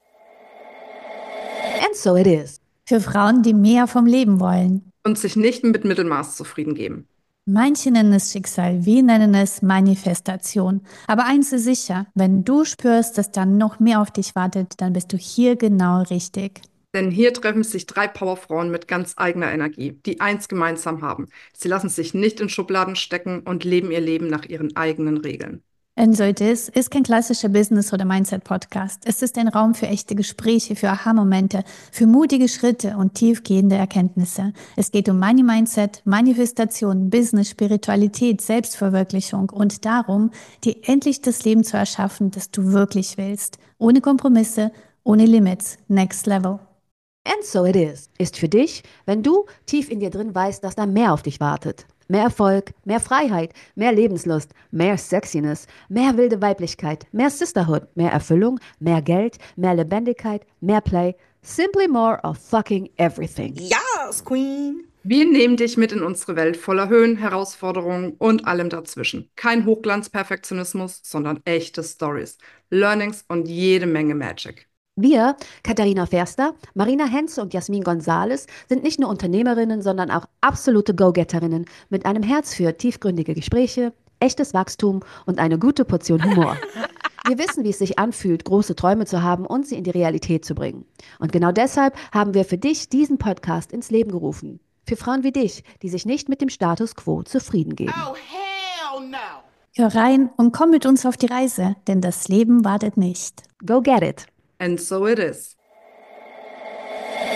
Hier treffen sich drei Powerfrauen mit ganz
– es ist ein Raum für echte Gespräche, für Aha-Momente, für mutige